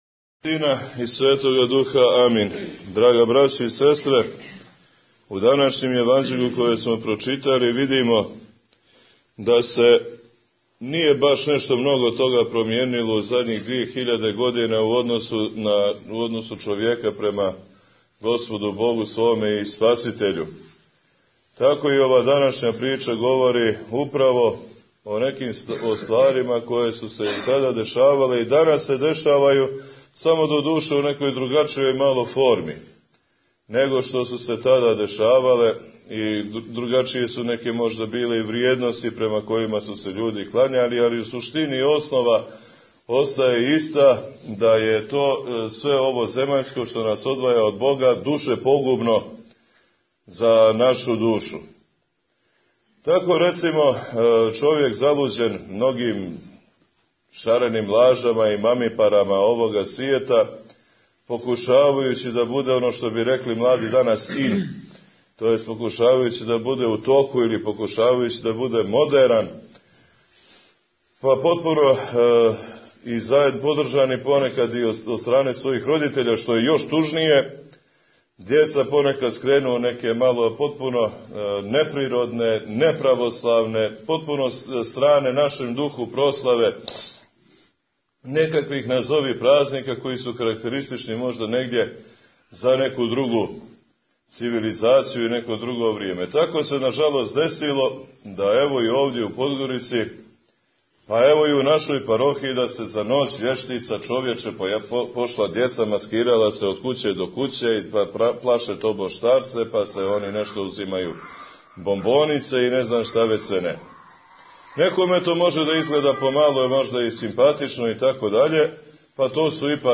Бесједа
Недељу по Духовима изговирена у Храму Светог Архангела Михаила у Дољанима.